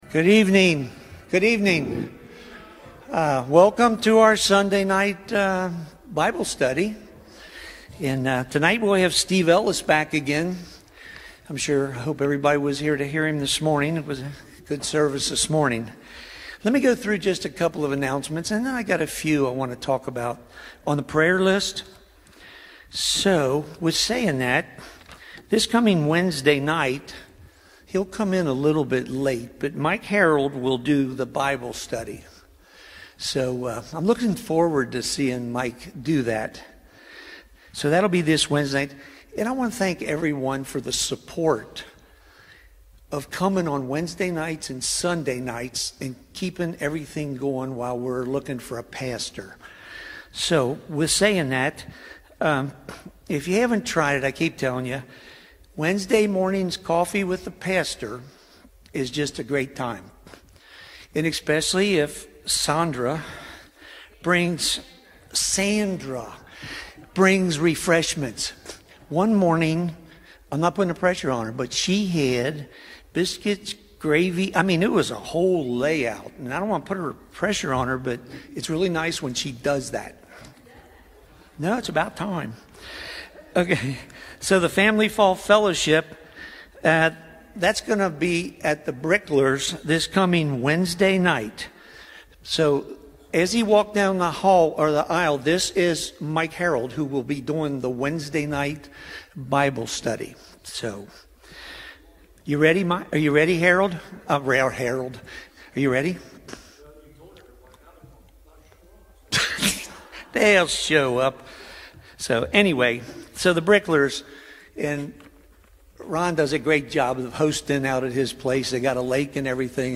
Services -- First Baptist Church Cold Spring Archives